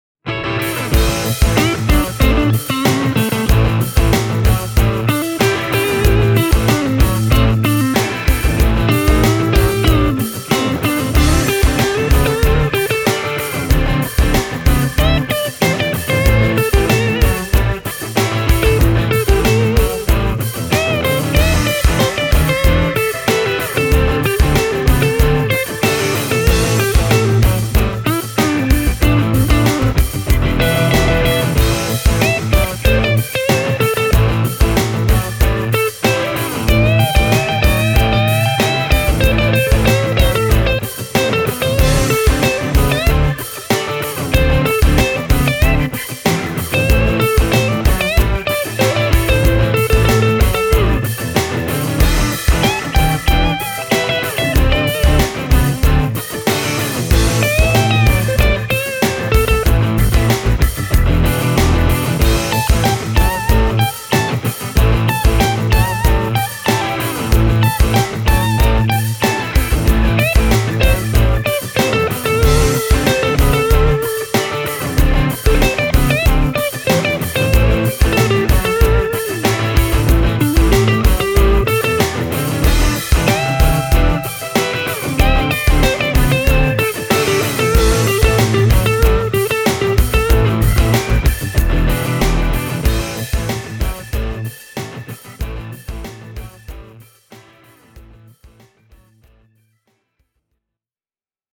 The second demo track features a Gibson Les Paul Junior (rhythm left), an Epiphone Casino (rhythm right), and a Fender Telecaster (lead guitar):